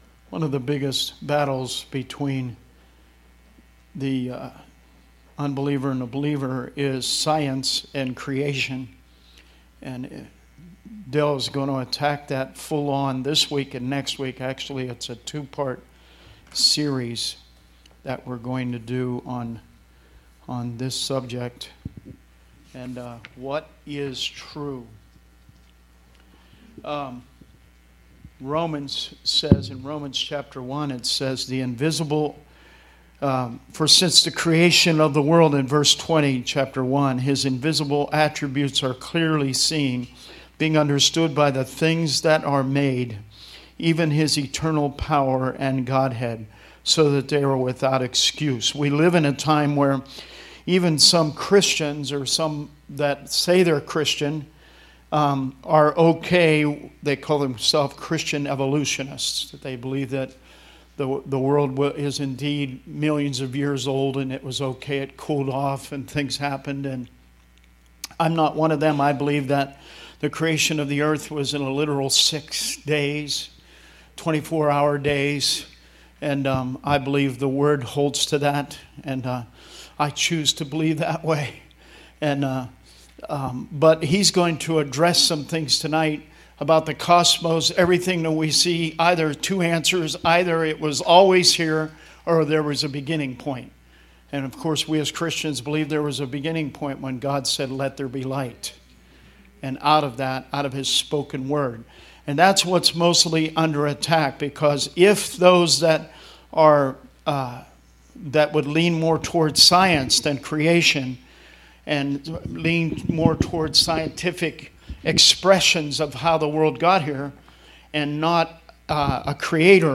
We continue our series watching Focus on the Family’s The Truth Project. Listen to discussion from Lesson 5 Part 1 – Science: What is True – Part 1.
The Truth Project Service Type: Wednesday Teaching Preacher